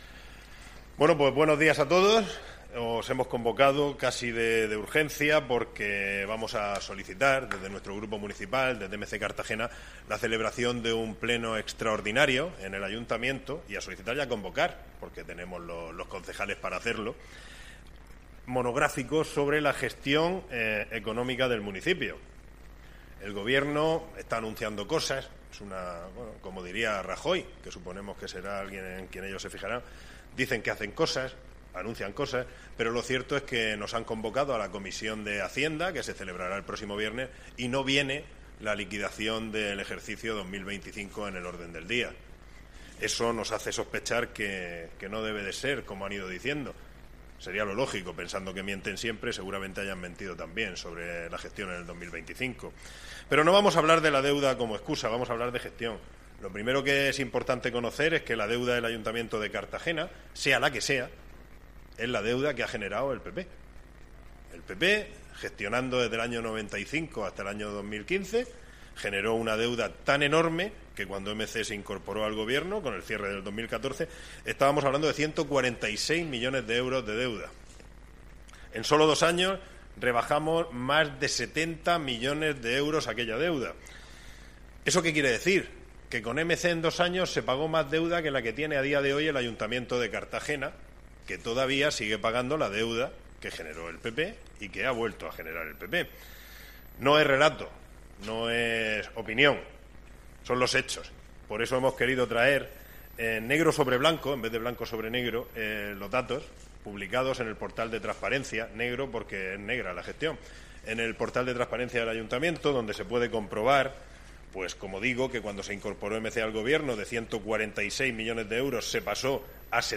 Audio: Declaraciones de Jes�s Gim�nez Gallo (MP3 - 5,11 MB)
El portavoz y líder de MC Cartagena, Jesús Giménez Gallo, ha comparecido este martes de urgencia ante los medios de comunicación para explicar la solicitud de un Pleno extraordinario y monográfico sobre la gestión económica del Ayuntamiento, con el objetivo de que el Gobierno local rinda cuentas de la evolución de la deuda, la subida de impuestos y la ejecución real de los recursos municipales durante los últimos años.